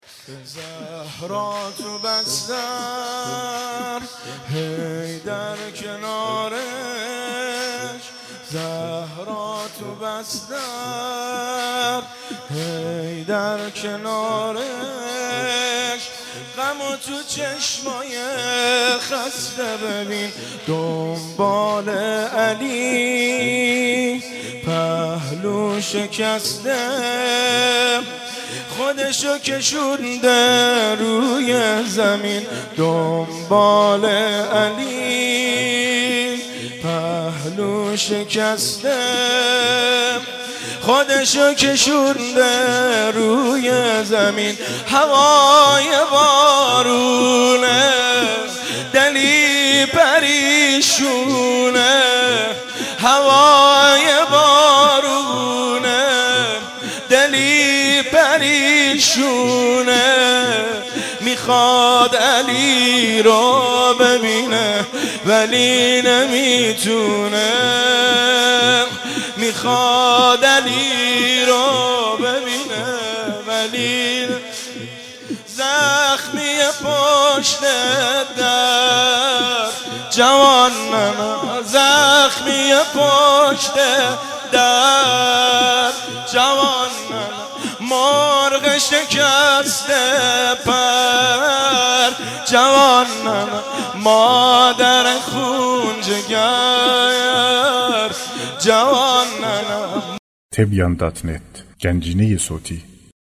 مداحی و نوحه
سینه زنی در شهادت حضرت فاطمه زهرا(س)